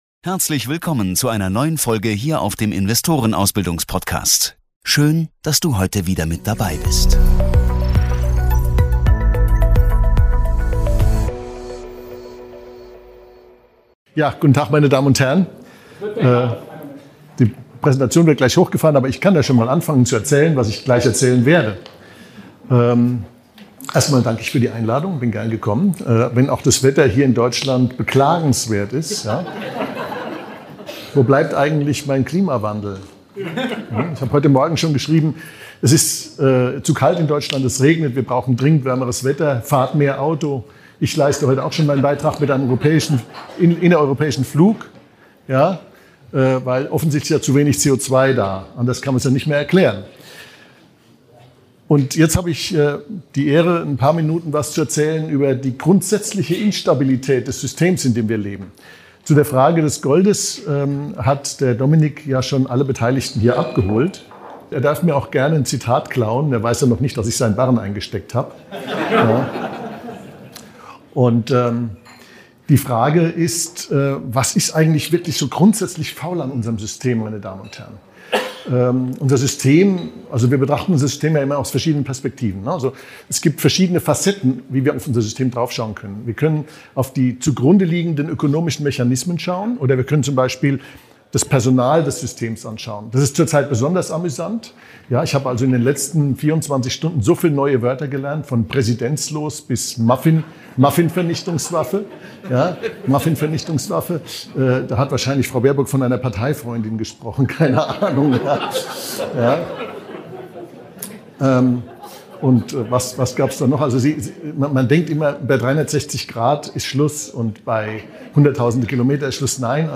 Beschreibung vor 1 Jahr In diesem Vortrag auf dem Kapitaltag am 19. April 2024 in Hannover analysiert Dr. Markus Krall kritisch das aktuelle Finanzsystem. Er erläutert den Übergang vom Gold- zum Fiat-Geld, warnt vor einer drohenden Hyperinflation und hinterfragt die Zukunft des US-Dollars als Leitwährung.